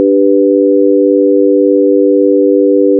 Listen to the tempered chord (G:B:D).
GBDchord2.wav